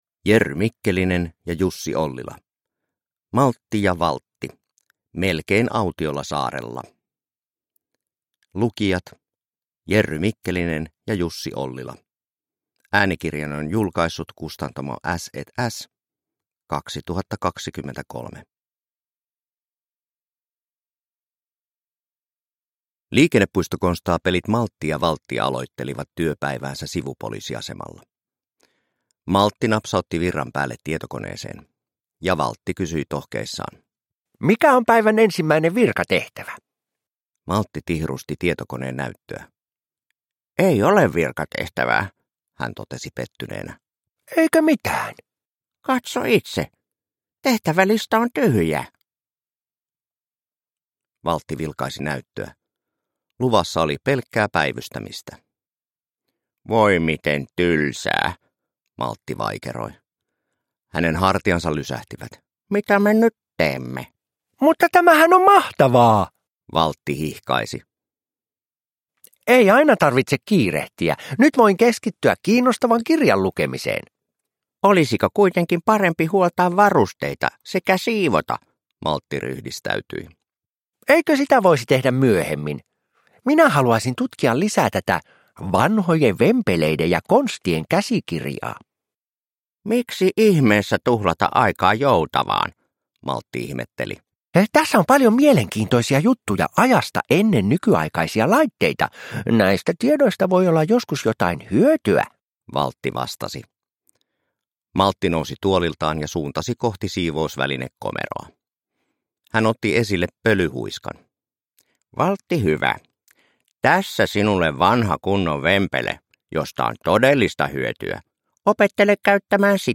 Maltti ja Valtti melkein autiolla saarella – Ljudbok – Laddas ner